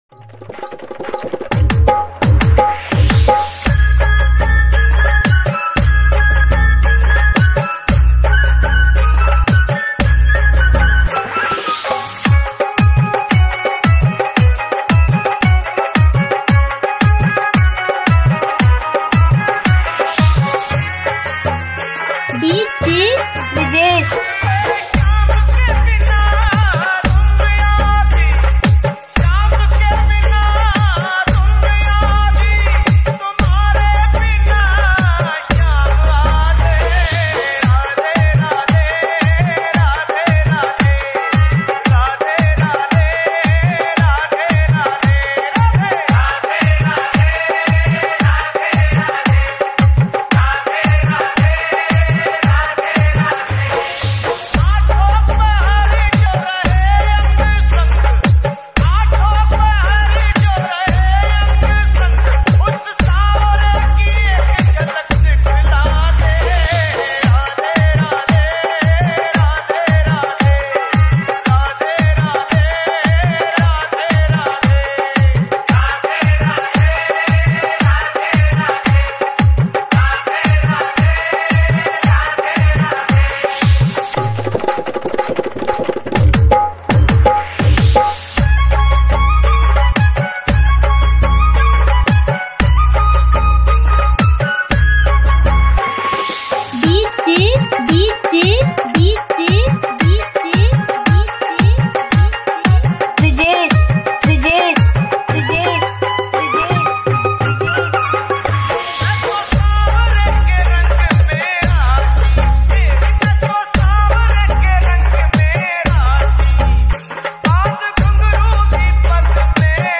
DJ remix
Hindi Bhakti Dj Remix